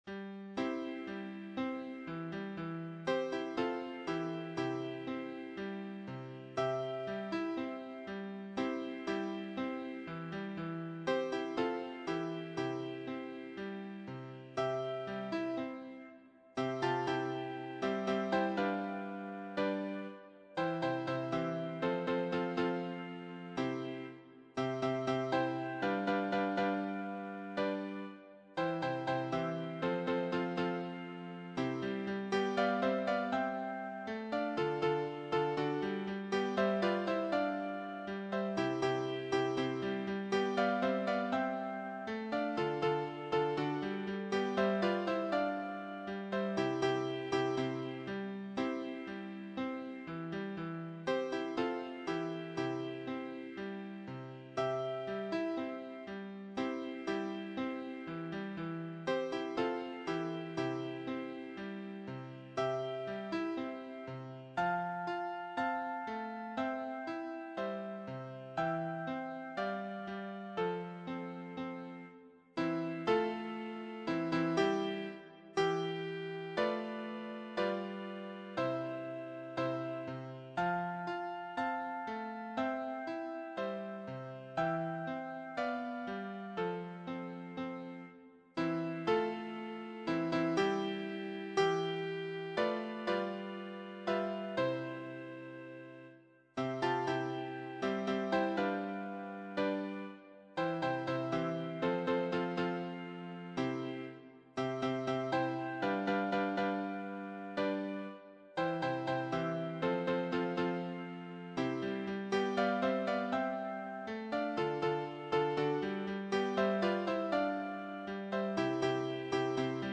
choir SATB